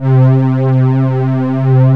P A D30 01-L.wav